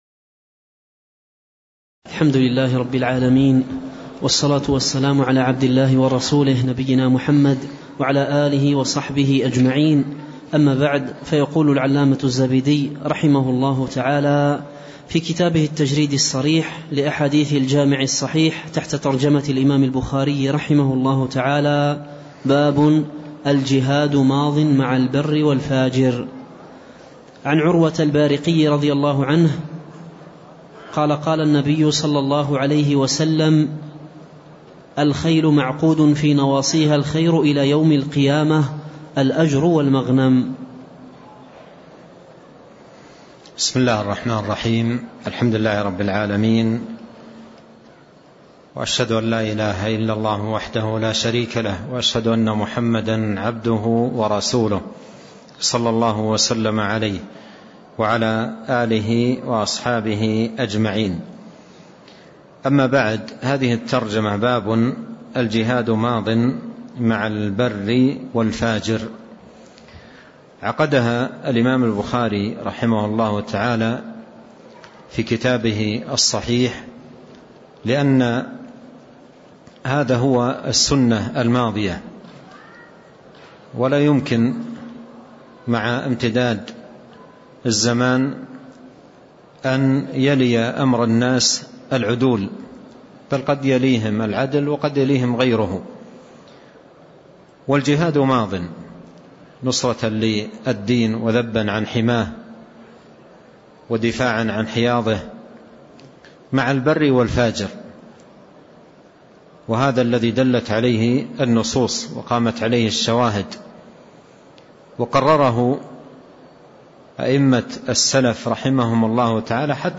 تاريخ النشر ١٩ صفر ١٤٣٥ هـ المكان: المسجد النبوي الشيخ